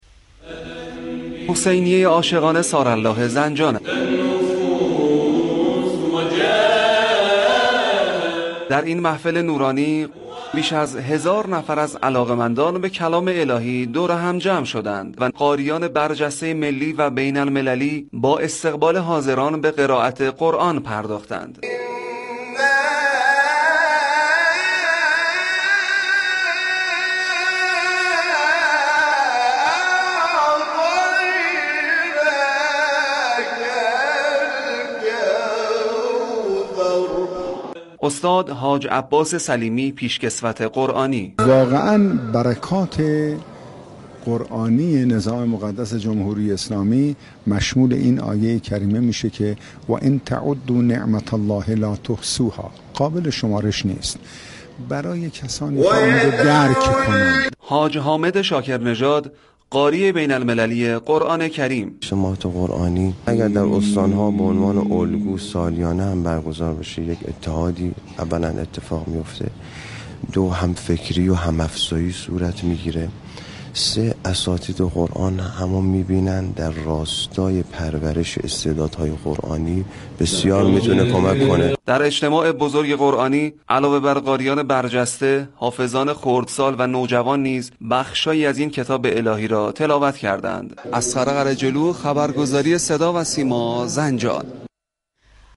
اجتماع قرآنی و محفل انس با قرآن كریم در محكومیت اهانت به كلام وحی در جریان اغتشاشات با حضور مردم زنجان در حسینیه عاشقان ثارالله این شهر برگزار شد. تلاوت قاریان بین المللی قرآن كریم اجرای گروه های سرود و تواشیح نوجوانان و نونهالان از دیگر برنامه های این اجتماع قرآنی بود.